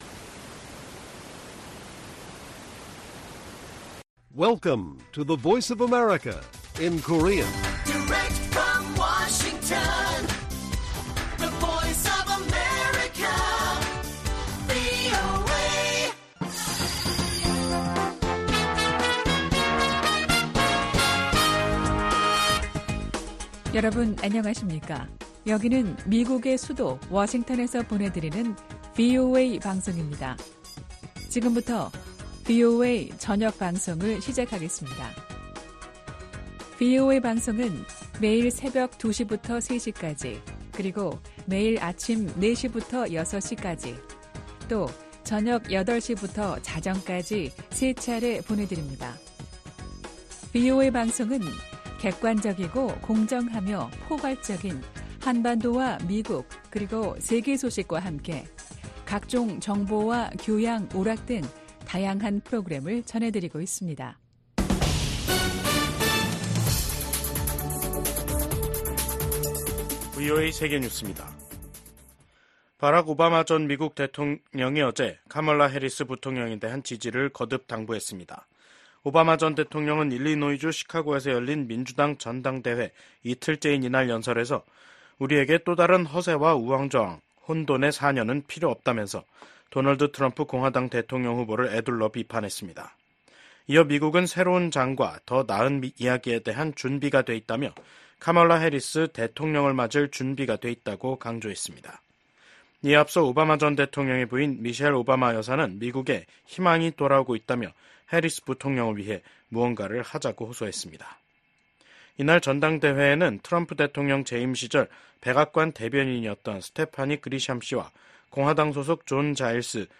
VOA 한국어 간판 뉴스 프로그램 '뉴스 투데이', 2024년 8월 21일 1부 방송입니다. 미국 국방부는 미한 연합훈련인 을지프리덤실드 연습이 방어적 성격이란 점을 분명히 하며 ‘침략 전쟁 연습’이란 북한의 주장을 일축했습니다.